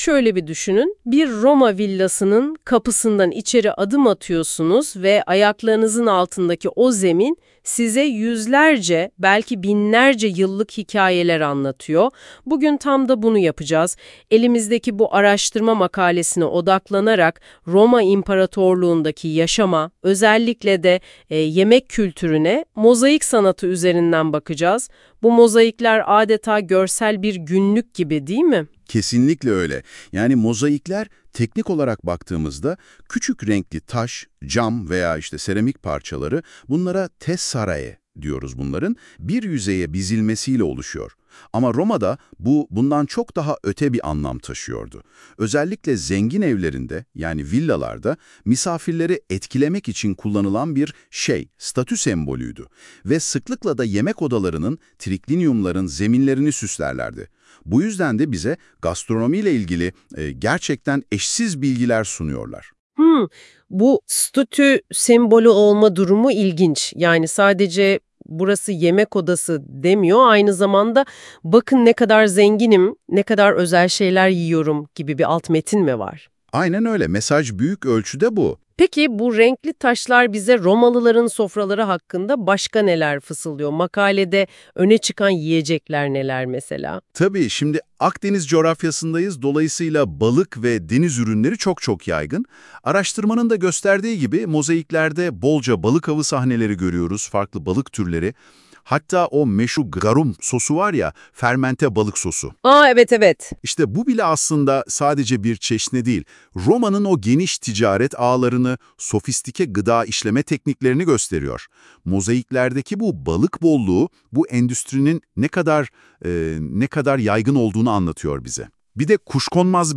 Mozaik Sanatı, Gastronomi, Söyleşi